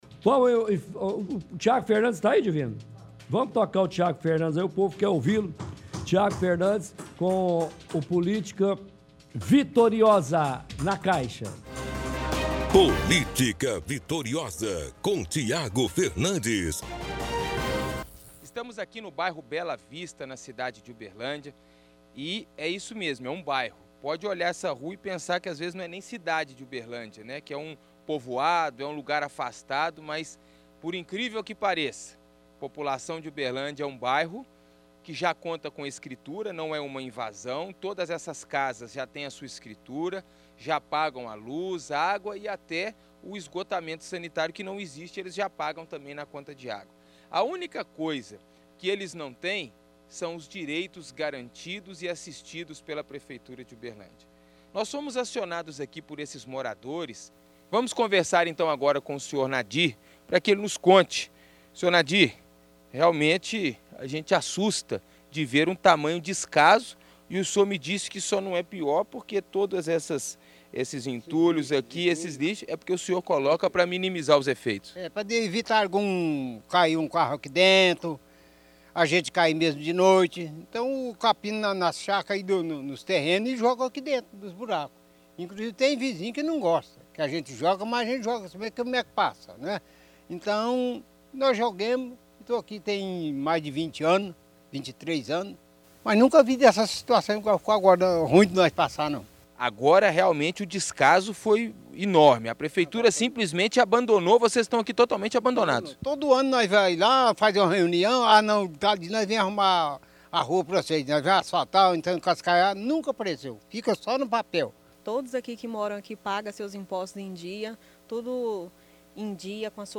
– Transmissão de áudio da reportagem de hoje do Chumbo Grosso.